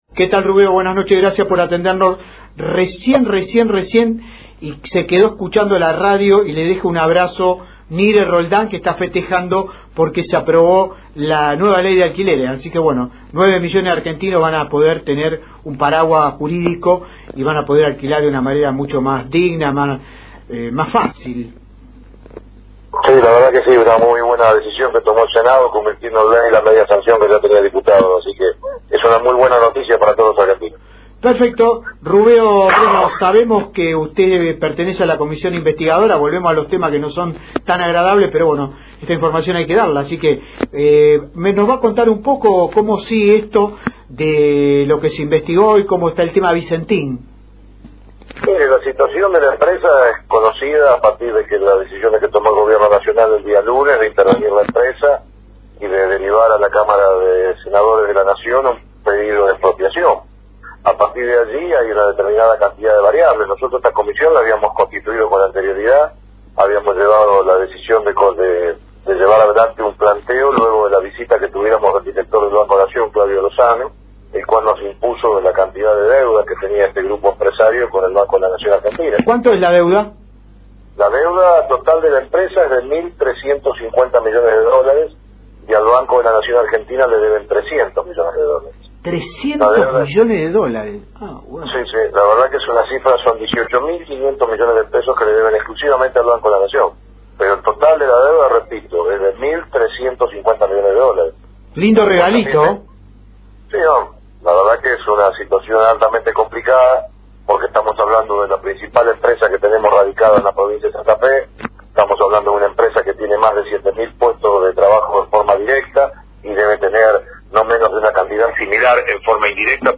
En entrevista en el programa Futuro Regional que se emite por LT3, el diputado Provincial Luis Daniel Rubeo detalla las acciones de la comisión de seguimiento del caso Vicentín